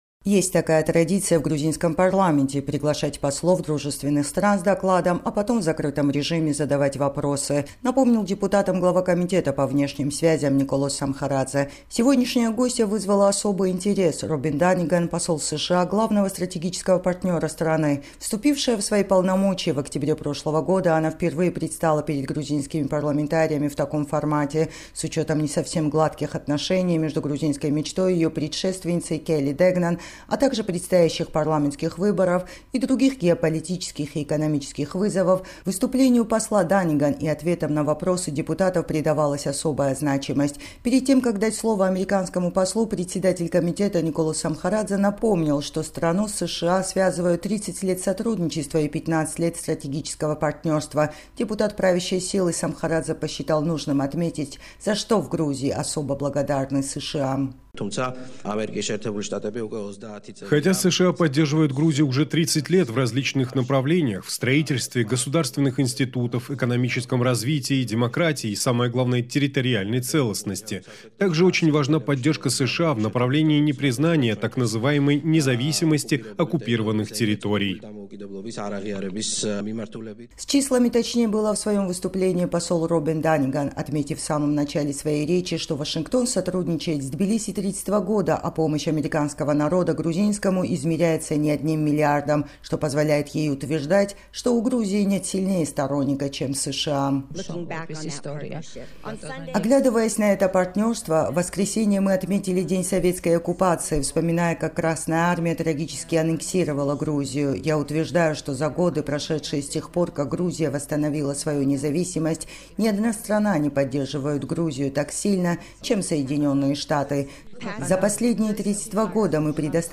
Впервые посол США в Грузии Робин Данниган предстала с отчетом перед грузинскими парламентариями.